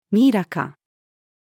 ミイラ化-female.mp3